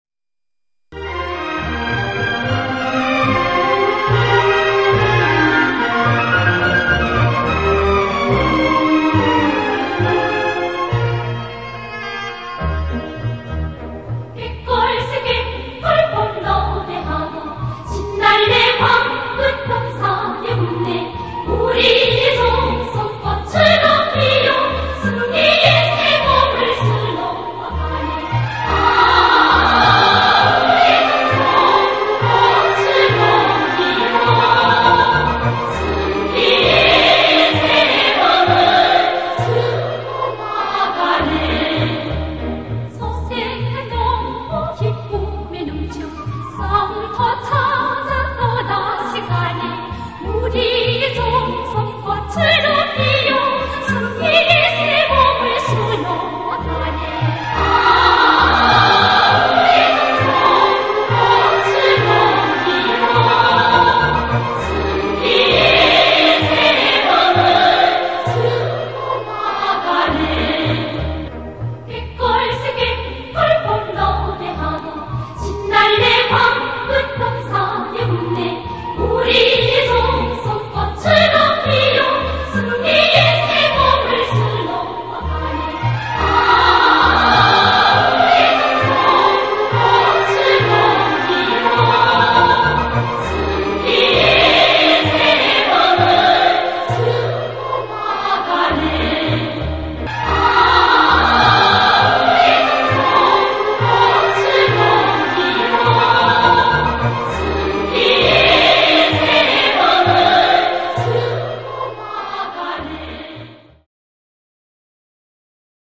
音质可能不是很好，且是单声道的。